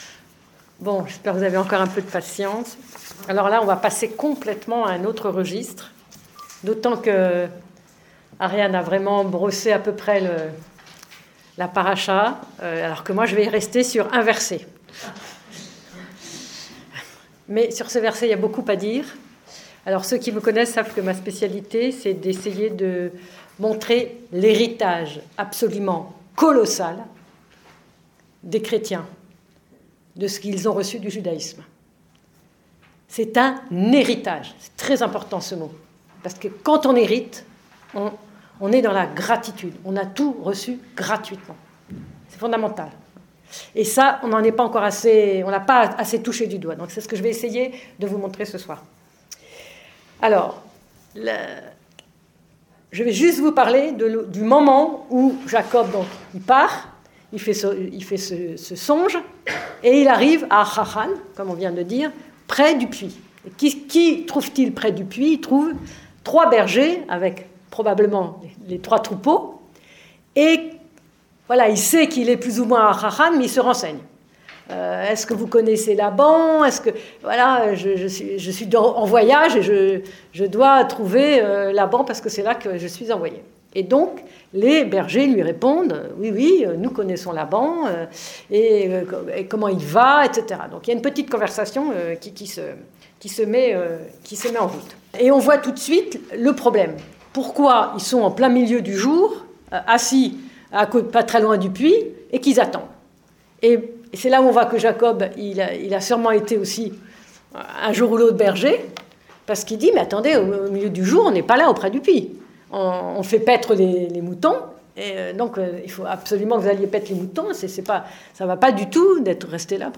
Dans le cadre d'un cycle de soirées entre juifs et chrétiens au Collège des Bernardins, une voix juive et une voix chrétienne proposent une étude sur une section biblique qui correspond à la lecture de l'office communautaire du shabbat matin.